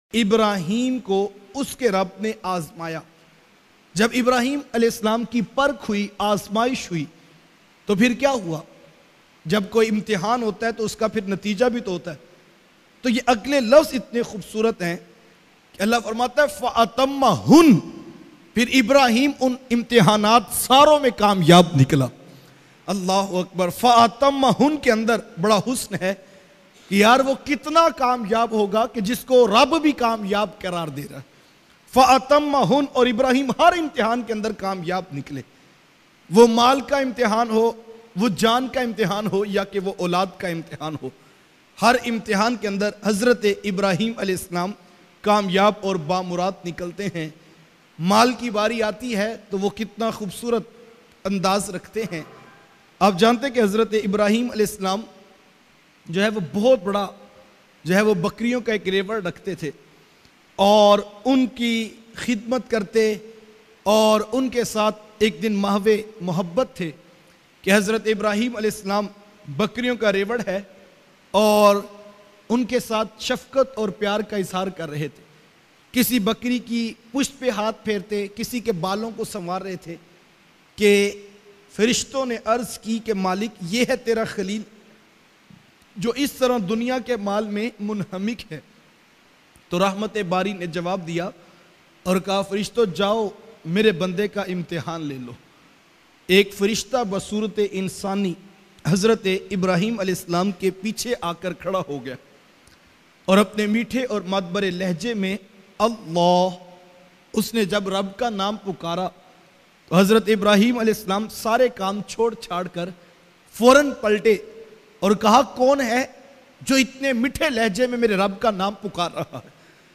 Hazrat Ibarhim Her Imtehan me Kamyab Nekly bayan mp3